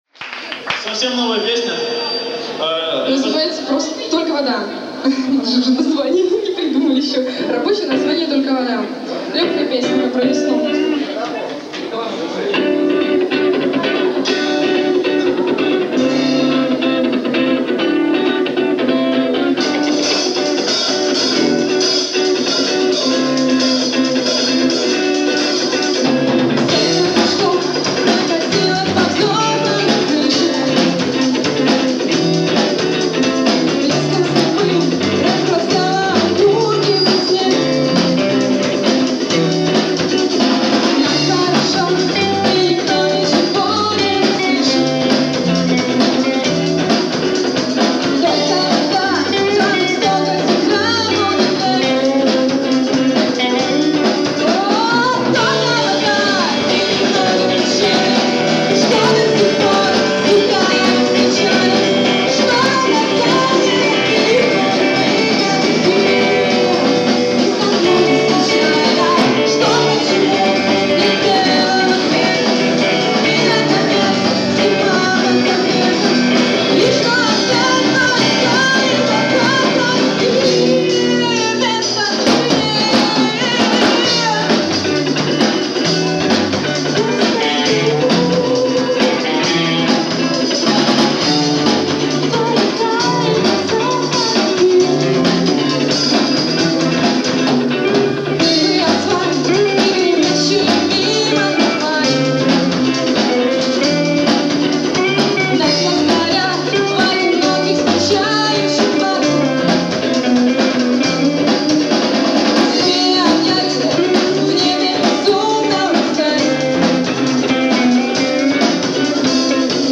Live in "Добрыя Мыслi" (31.03.06) Слова